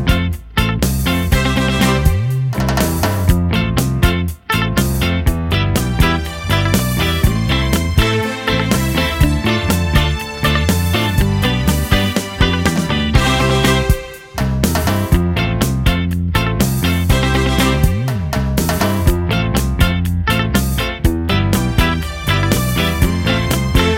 no Backing Vocals Soul / Motown 3:31 Buy £1.50